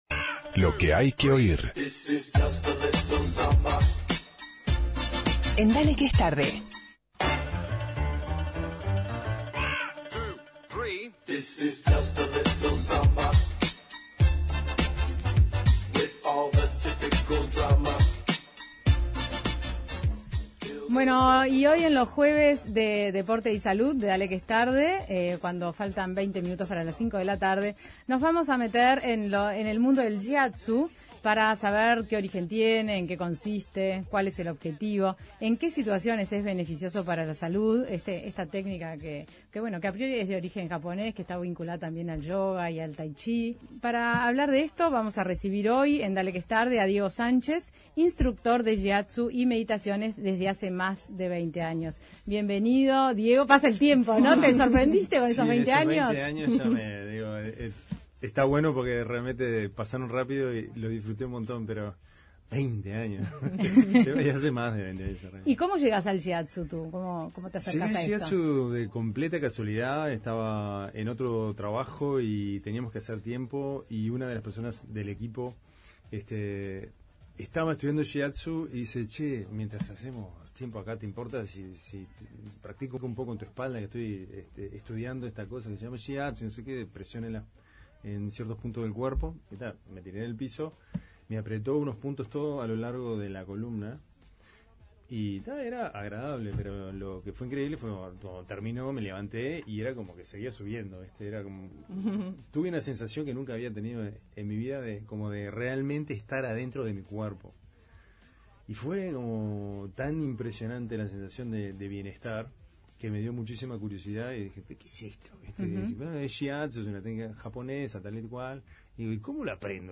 recibimos al instructor de Shiatsu y meditaciones